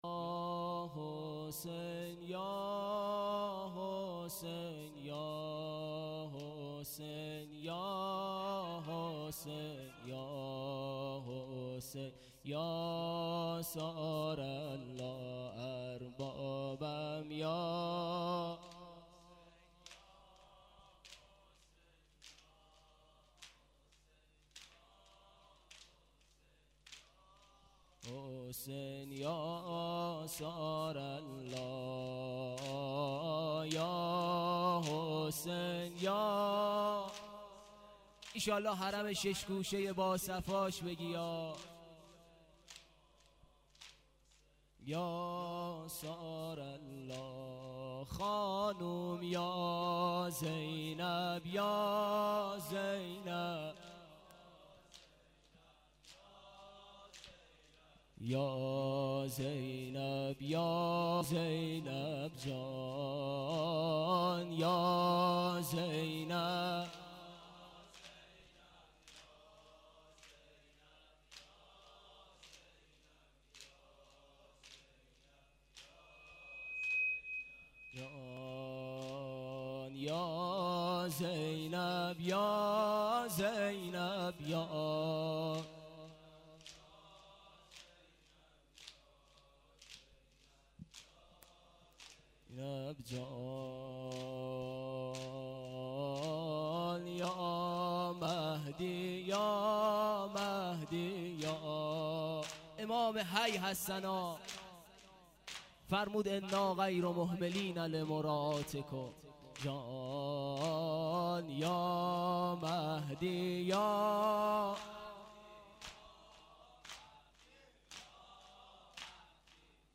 شب یازدهم ماه رمضان 1392- هیات لثارات الحسین(ع) - حوزه علمیه نخبگان